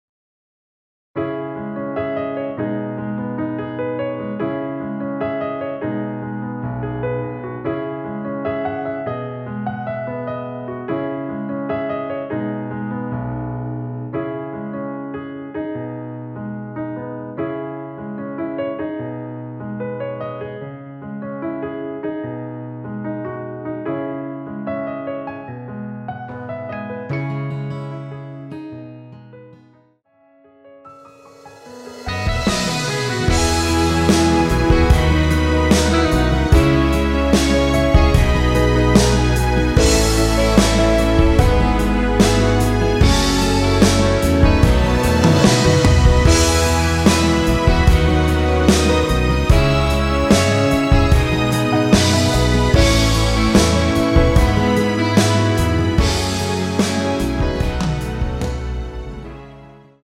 원키에서(+2)올린 MR입니다.
C#m
앞부분30초, 뒷부분30초씩 편집해서 올려 드리고 있습니다.
중간에 음이 끈어지고 다시 나오는 이유는